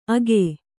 ♪ age